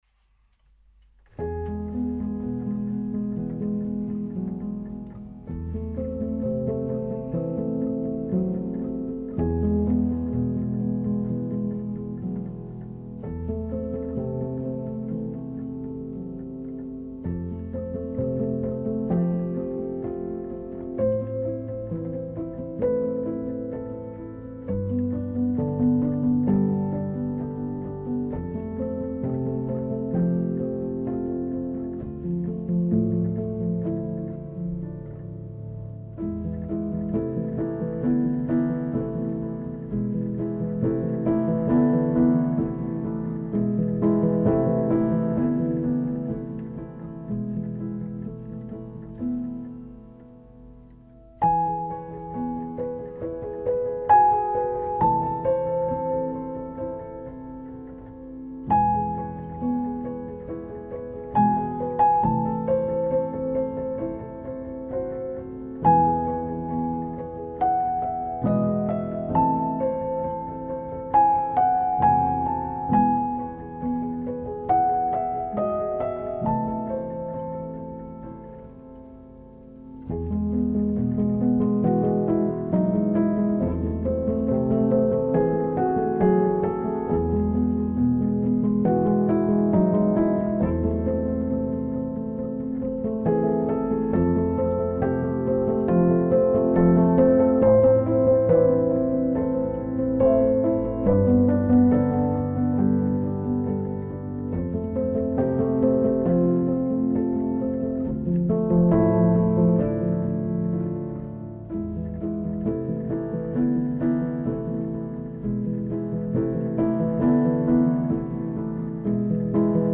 موسیقی بی کلام آرامش بخش پیانو عصر جدید
موسیقی بی کلام پیانو